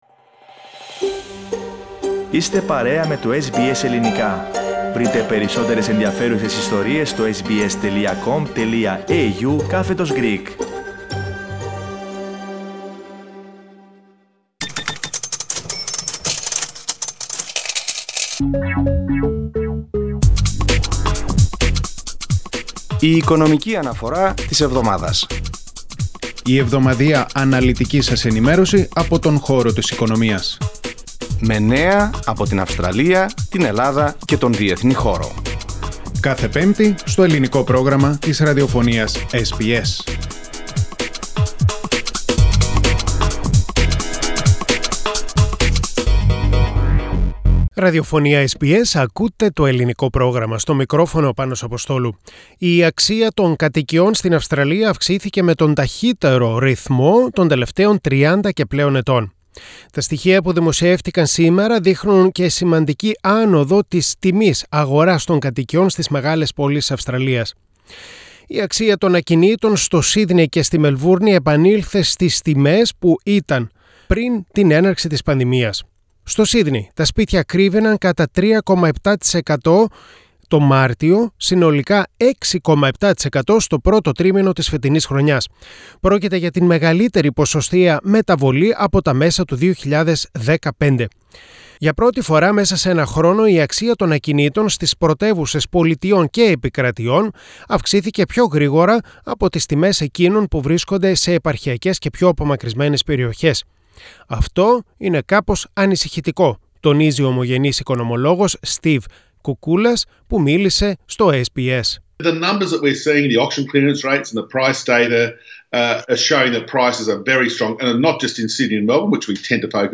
Finance Report: Australian house prices are rising at the fastest pace in 32 years, as the Sydney and Melbourne property markets stage a full recovery from the short-lived COVID downturn.